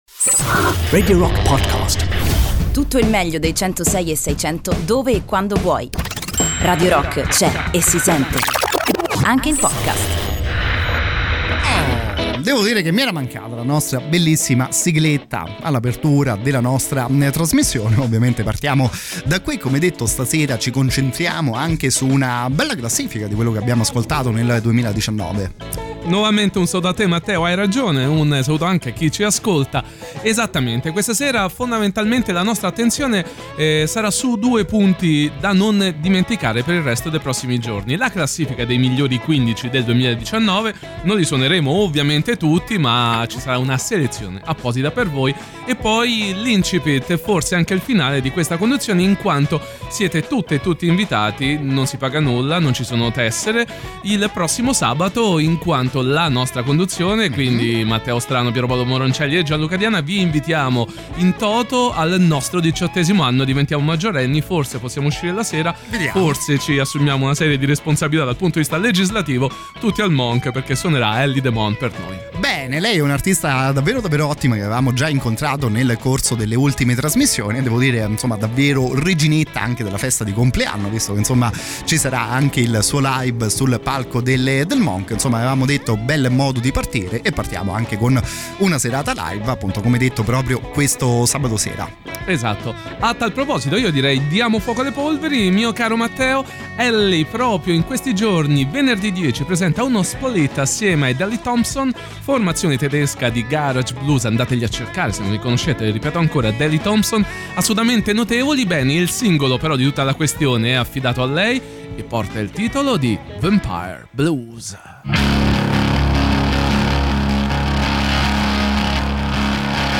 In diretta sui 106e6 di Radio Rock ogni martedì dalle 23:00